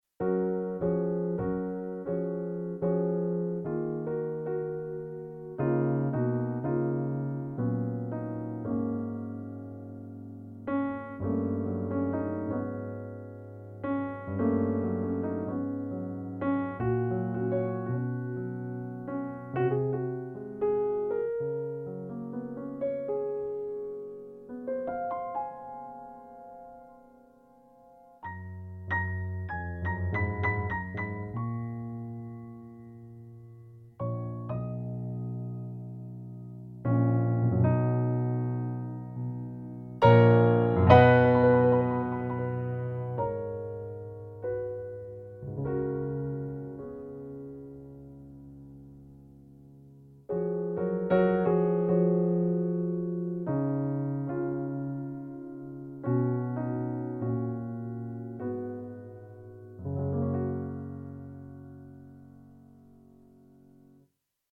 A soft and contemplative piece of piano music with some funny tensions.